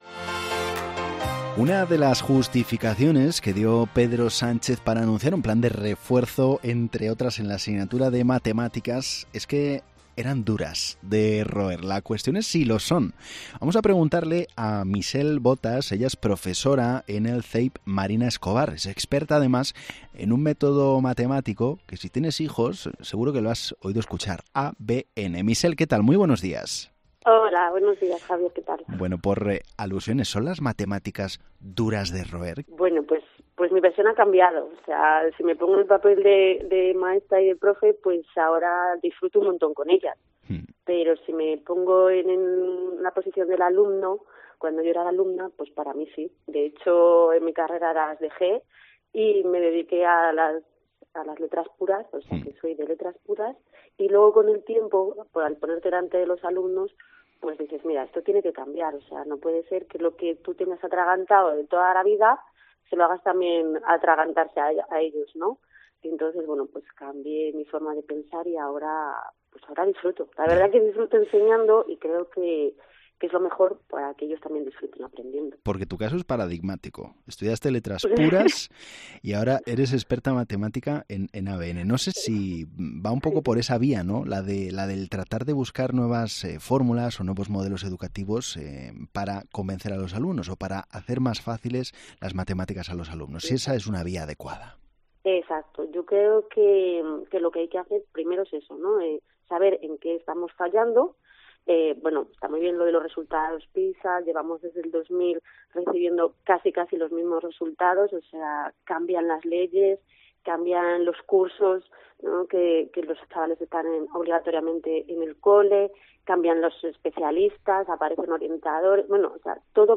¿Son las matemáticas duras de roer? La respuesta de una profesora, en COPE Valladolid